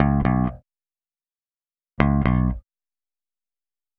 Power Pop Punk Bass Intro 01.wav